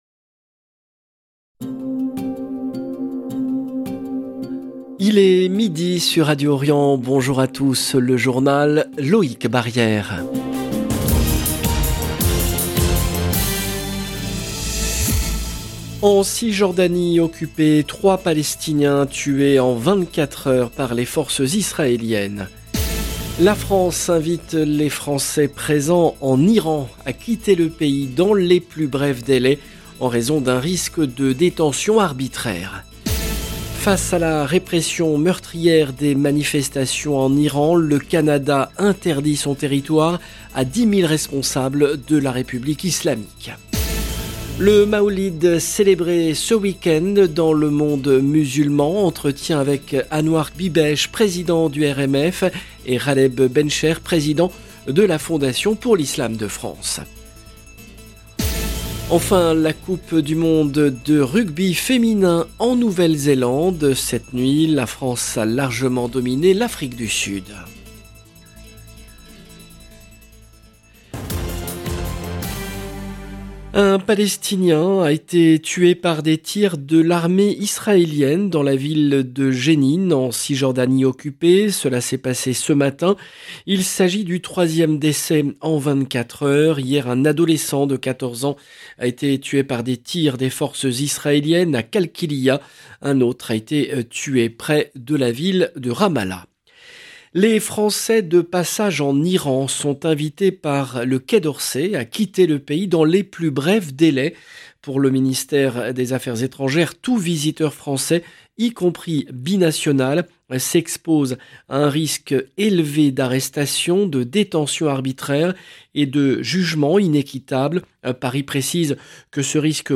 LE JOURNAL EN LANGUE FRANCAISE DE MIDI DU 8/10/22